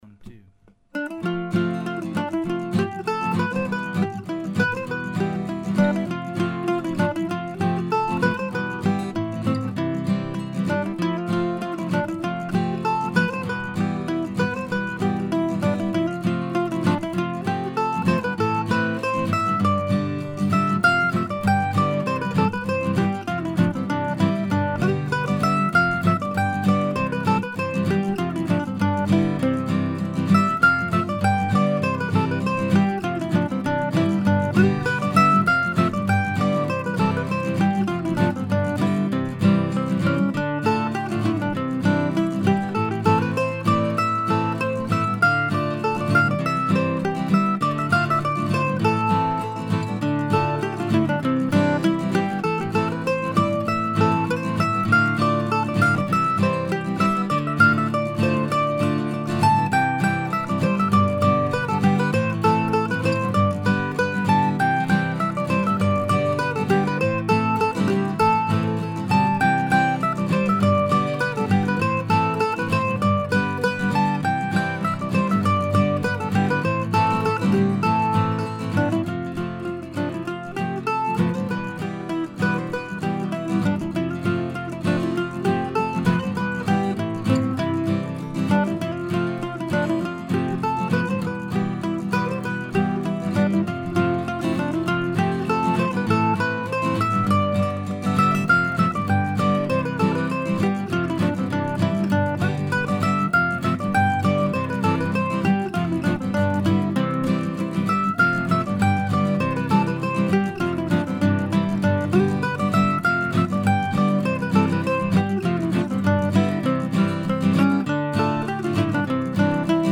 I think of this one as another in a long line of attempts to emulate the great mandolin tunes of Norman and Nancy Blake.
As usual these were all recorded using my now trusty Zoom H4 recorder.